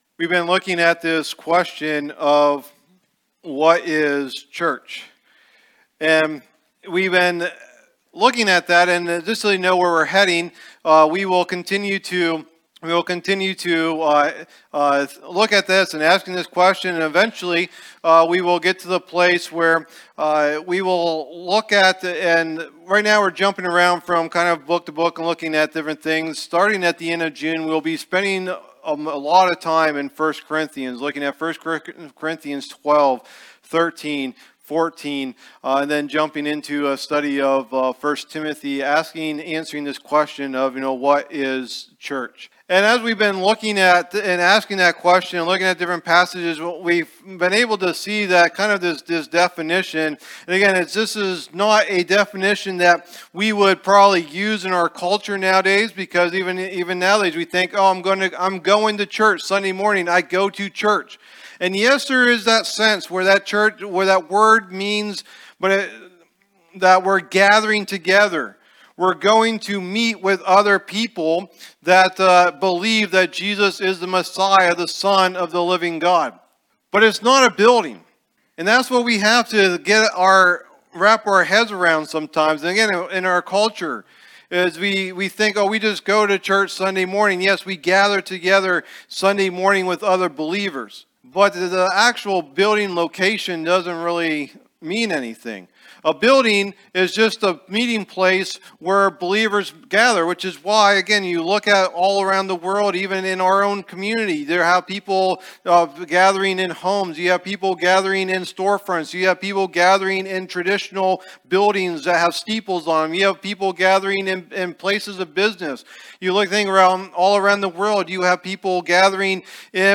Message #4 in the "What is Church?" teaching series